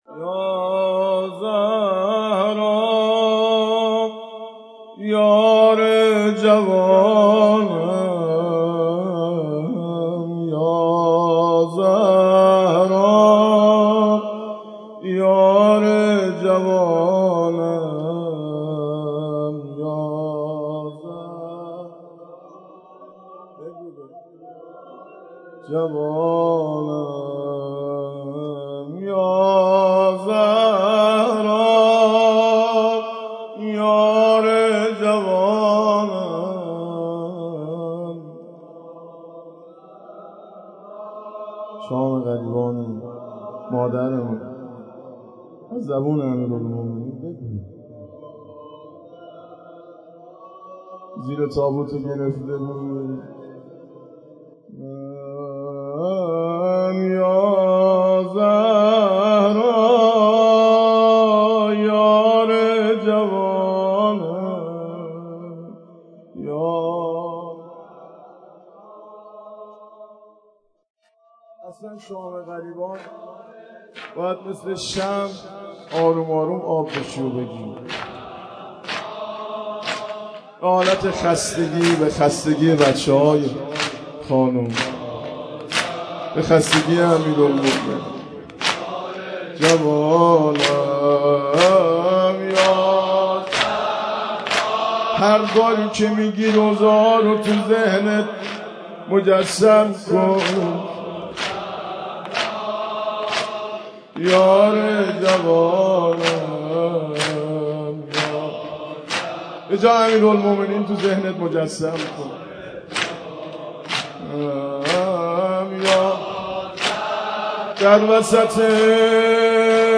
سینه زنی در شهادت مادر پهلو شکسته حضرت زهرا(س